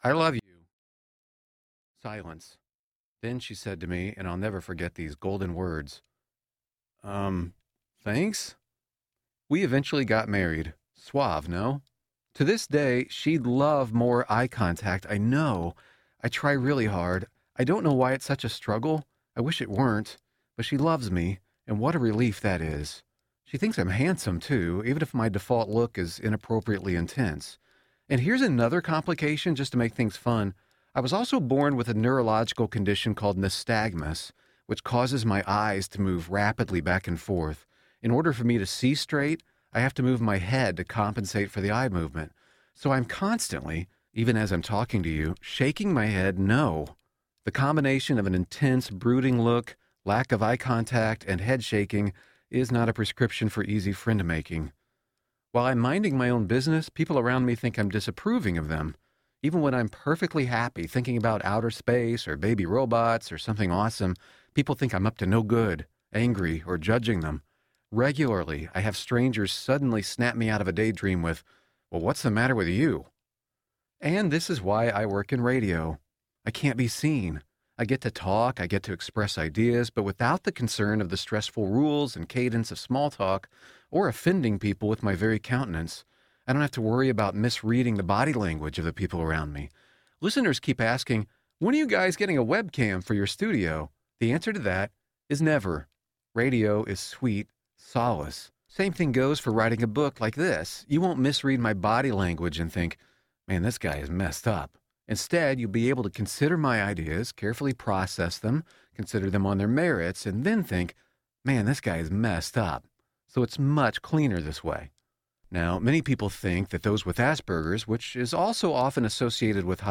Blessed Are the Misfits Audiobook